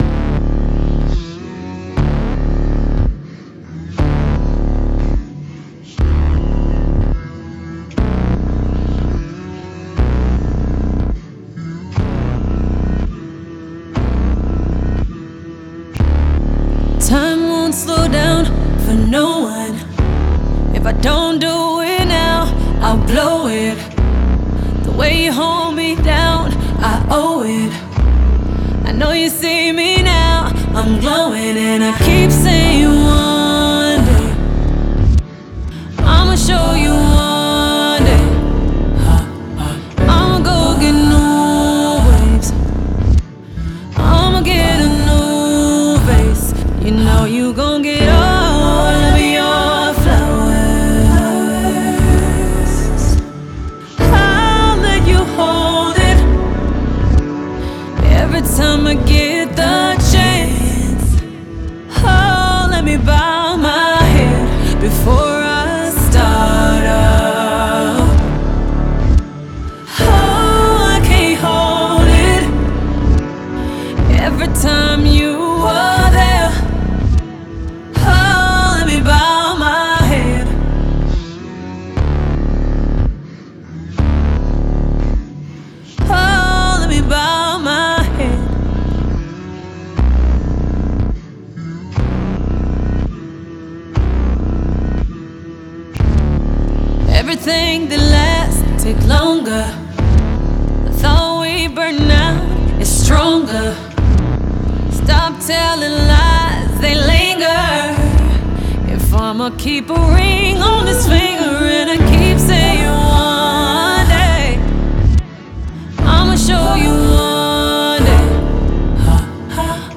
в жанре R&B